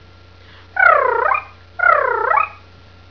chirp sounds from the emptiness